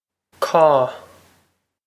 Caw
This is an approximate phonetic pronunciation of the phrase.